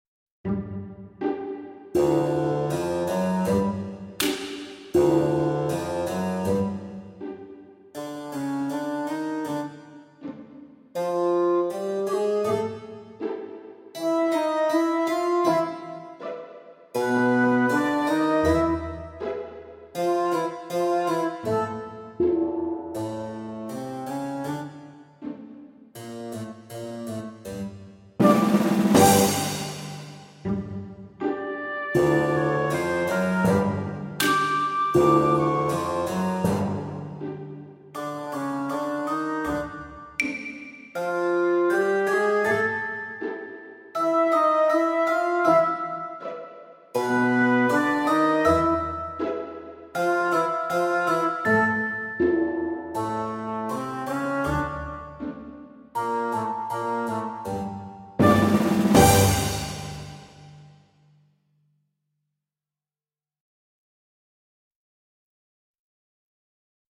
VS Trick or Treat (backing track)